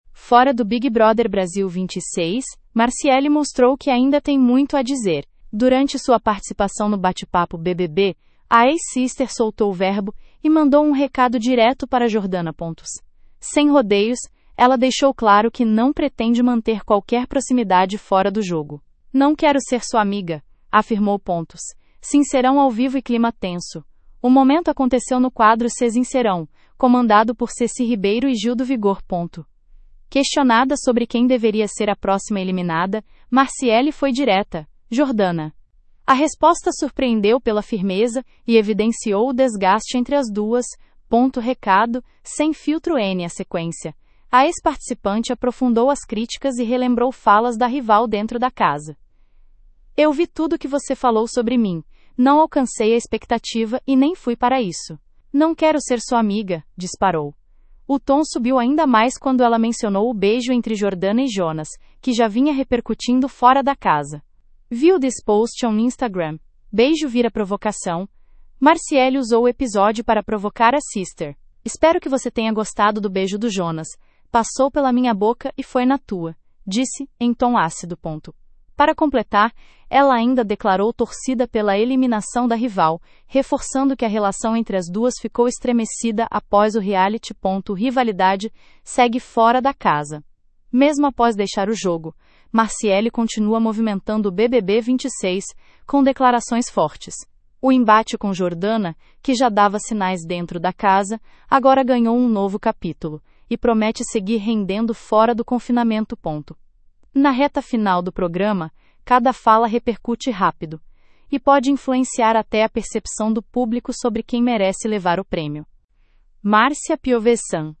Sincerão ao vivo e clima tenso